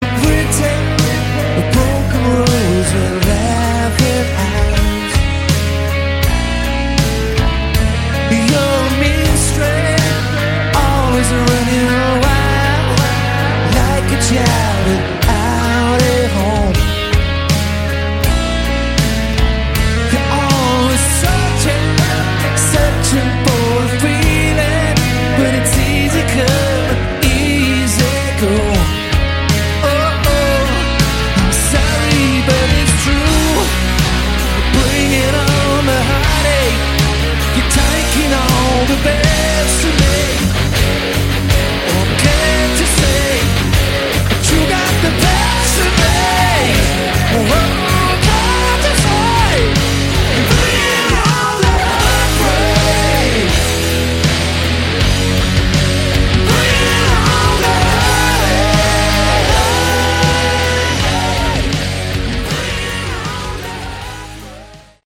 Category: Hard Rock
Recorded on their Las Vegas residency at 'The Joint' in 2013